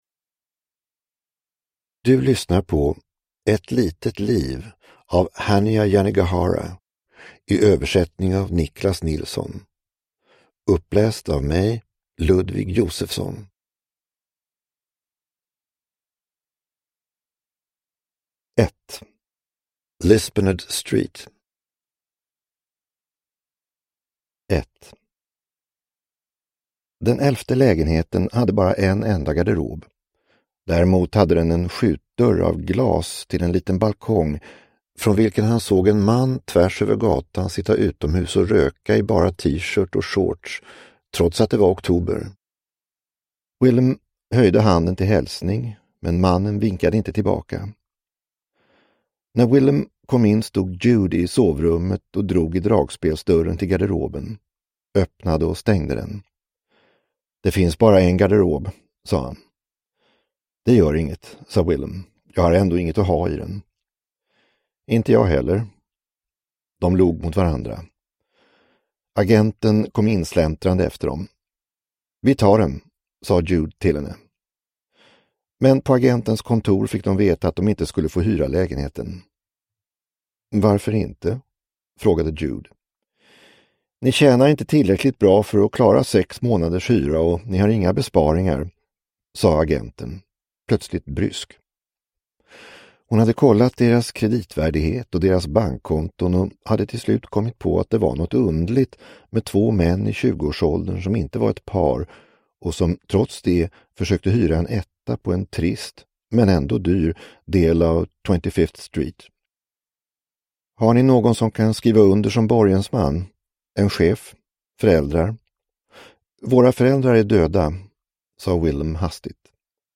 Ett litet liv – Ljudbok – Laddas ner